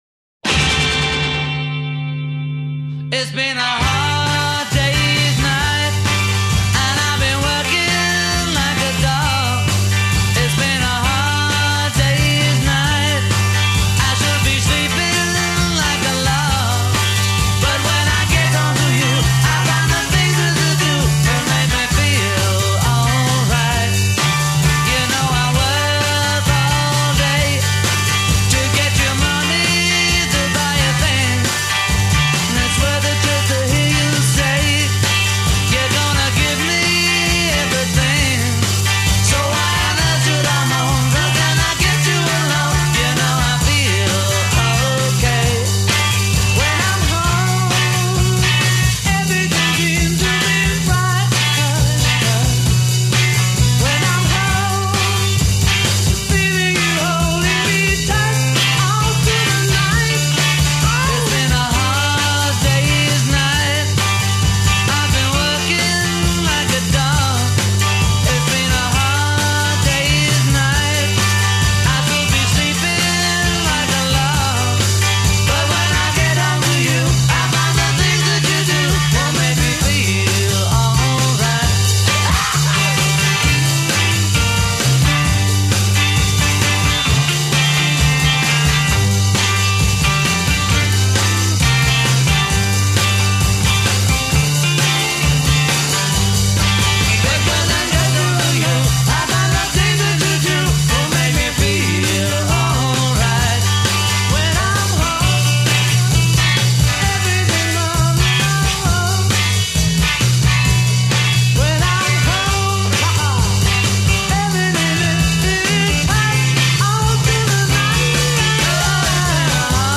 Take one, recorded 16 April 1964.
intro 0:00 1 experiment with opening chord
A verse a : 4+4 guitar solo
verse b : 4 vocal harmony a'
coda : 12+ Repeat last line, then repeat chord to flub.